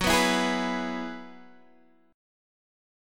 F#9sus4 chord